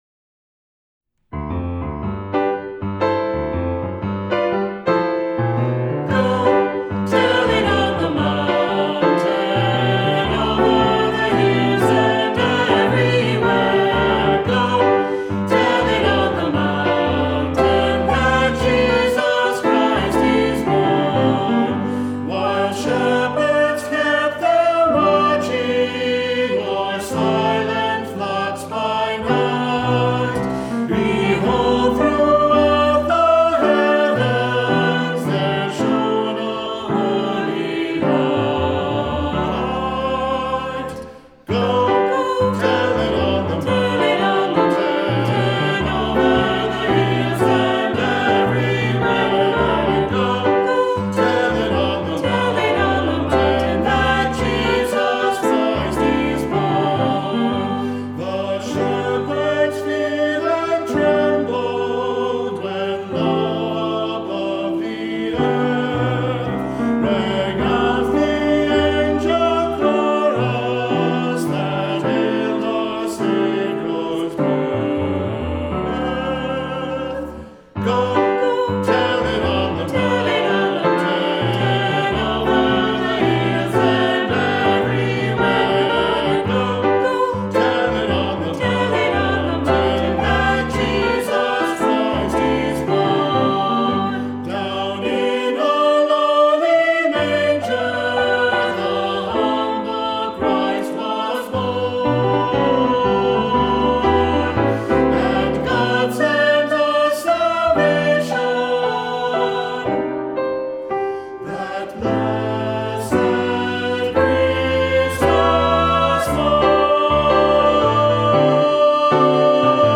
Voicing: Three-part Choir